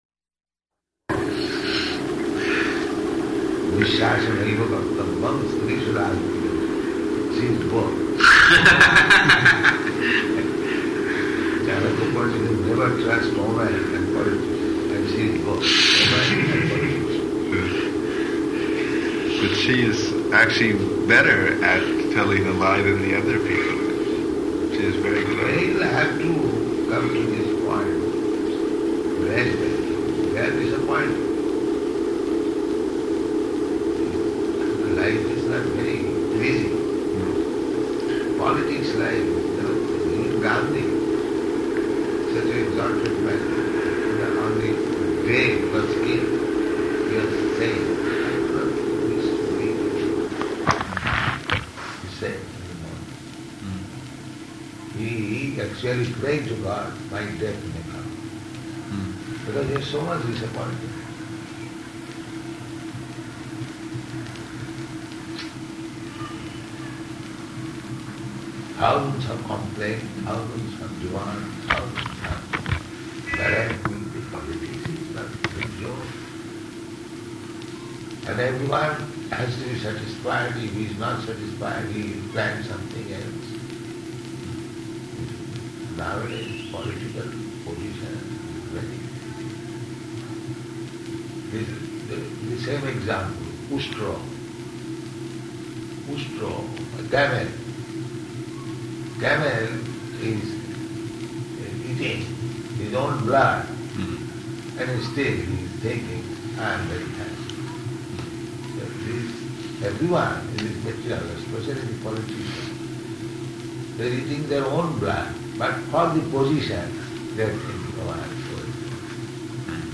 Room Conversation
Location: Delhi